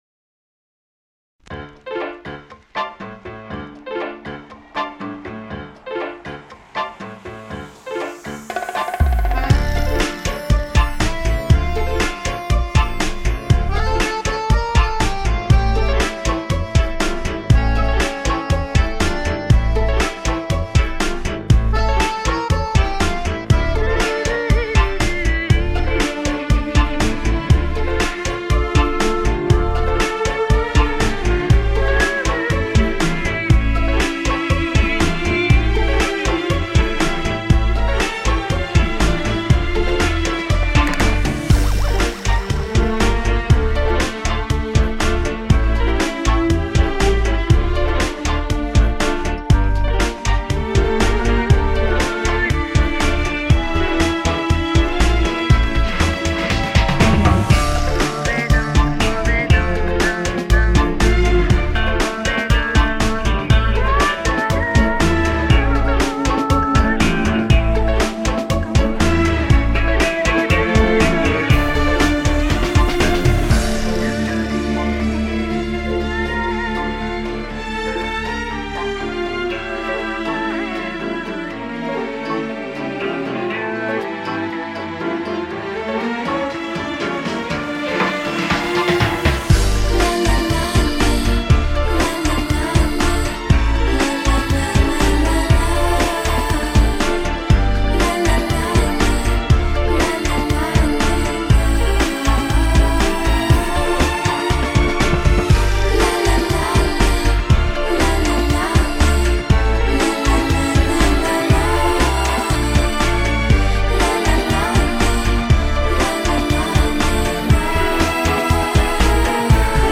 Tango Fusion бек вокал